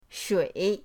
shui3.mp3